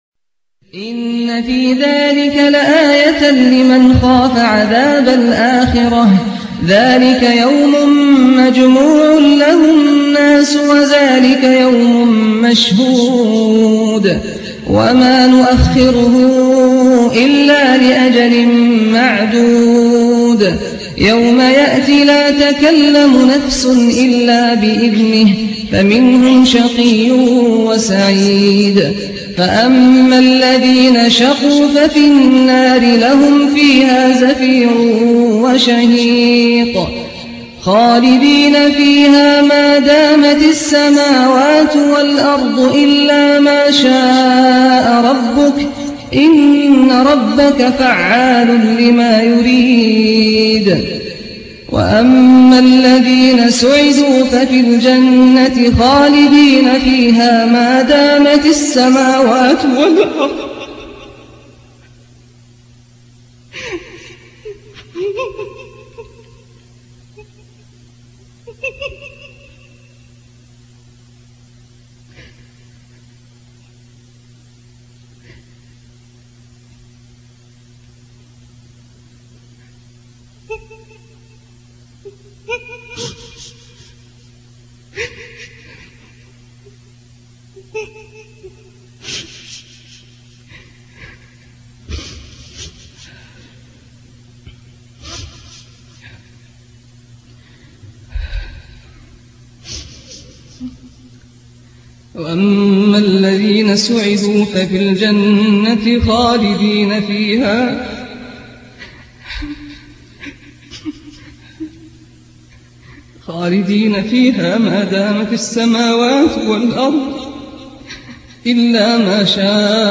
Surah Hud Recitation by Sheikh Saad Al Ghamdi
A very beautiful and emotional recitation of Surah Hud in the voice of Sheikh Saad Al Ghamidi.